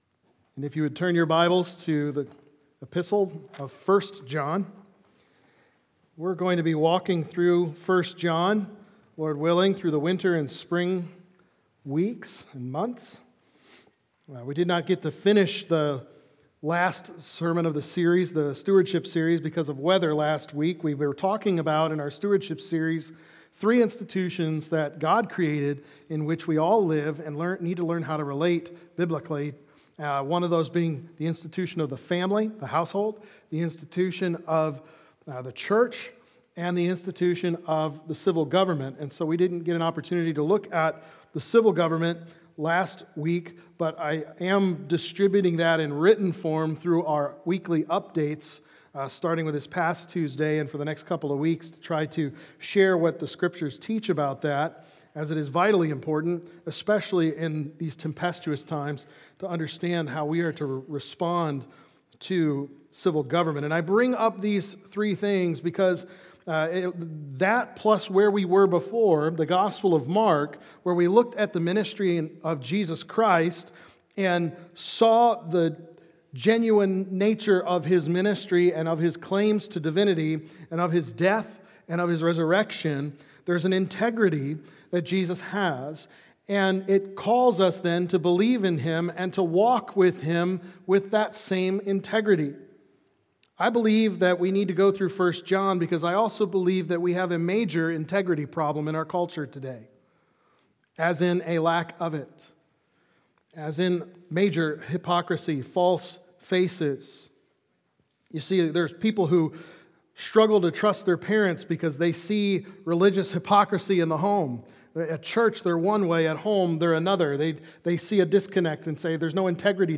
Living In The Light | Baptist Church in Jamestown, Ohio, dedicated to a spirit of unity, prayer, and spiritual growth